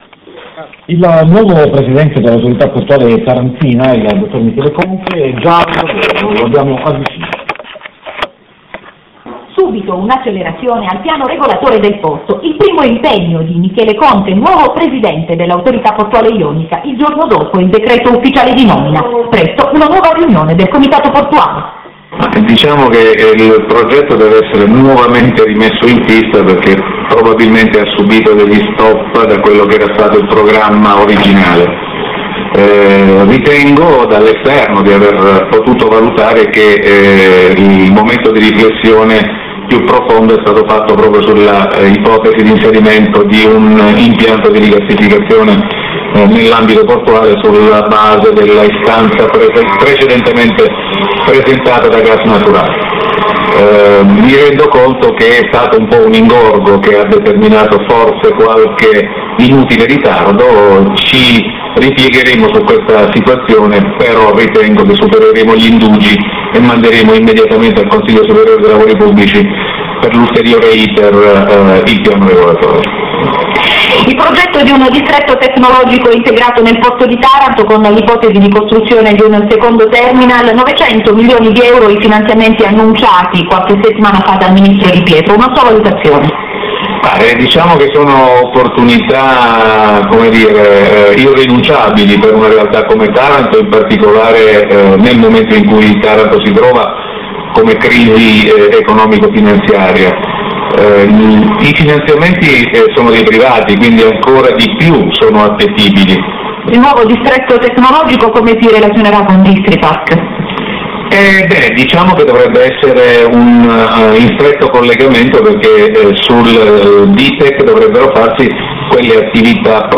Intervista di Studio100 a Michele CONTE